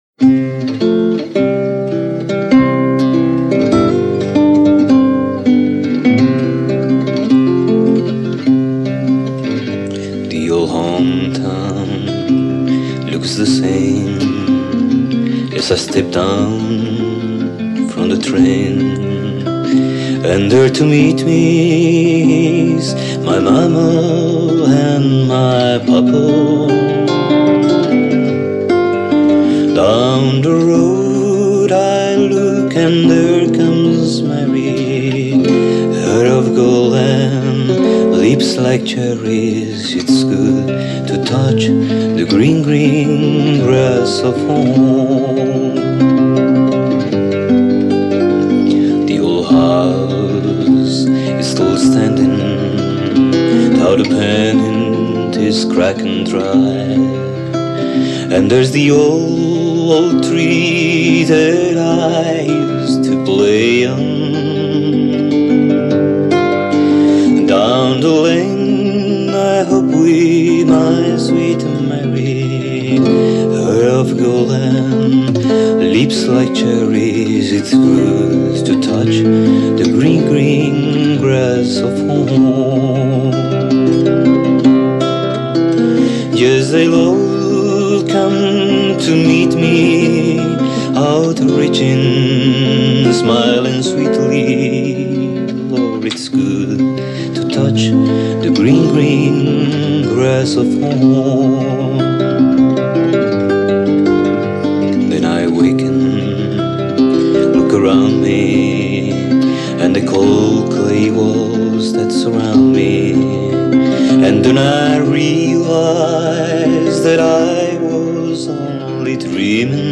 Guitarra y voz